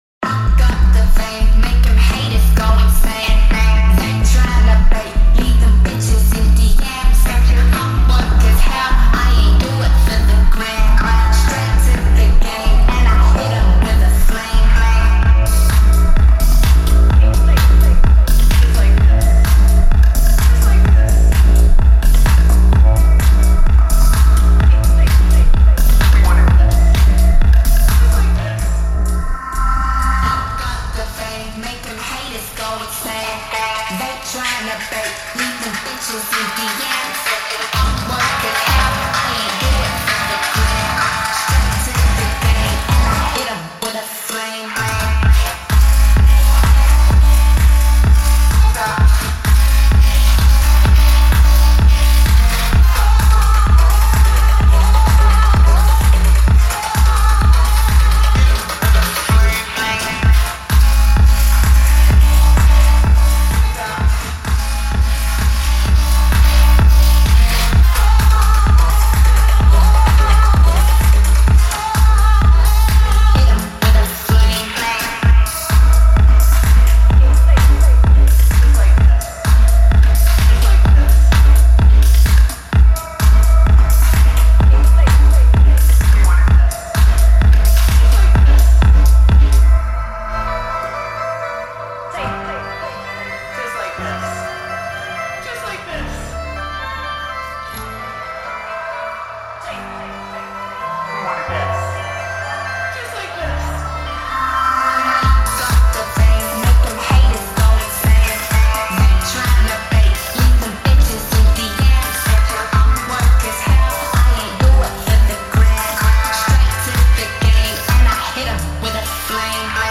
Трек размещён в разделе Зарубежная музыка / Поп.